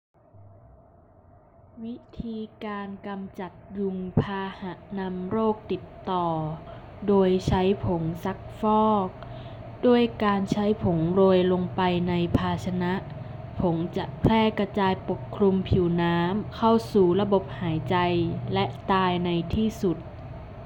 ★実際にニュースを読み上げたものはこちら。